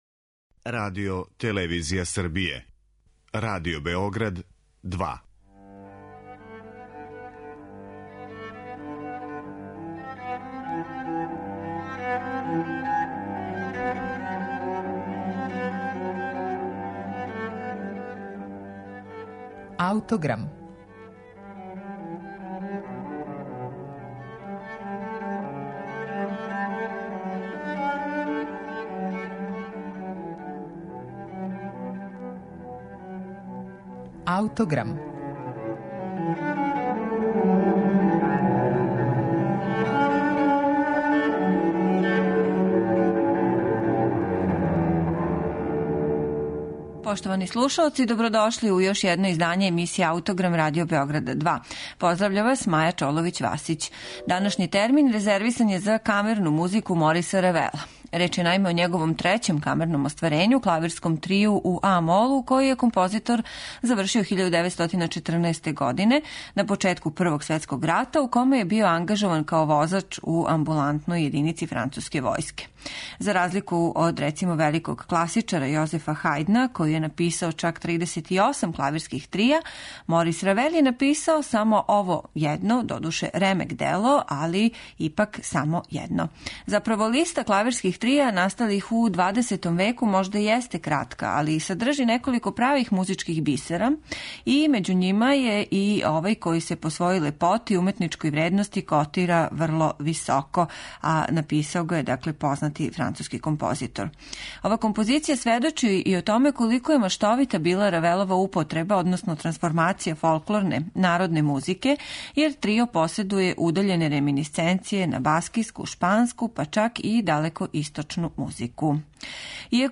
Морис Равел - Клавирски трио у а-молу.
Трио, наиме поседује удаљене реминисценције на баскијску, шпанску и чак далекоисточну музику. Слушаћемо га у извођењу ансамбла Trio di Parma.